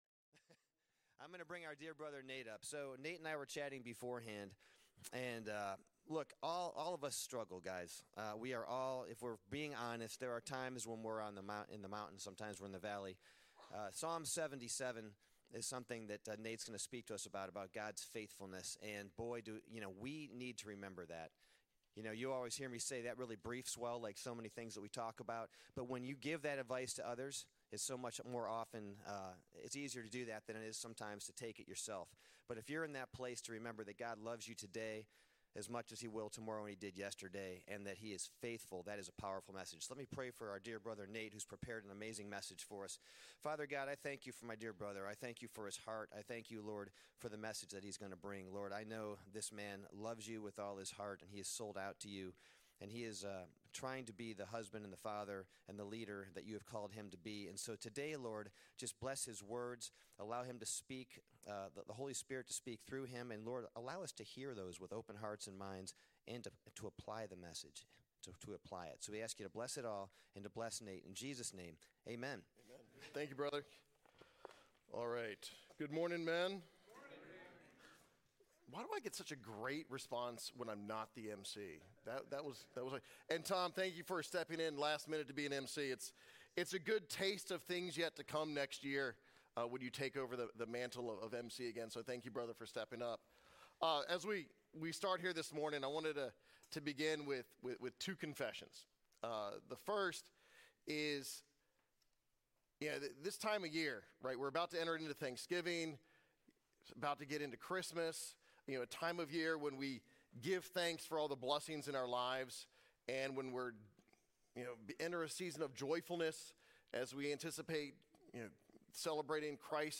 Thanksgiving Lesson – Psalm 77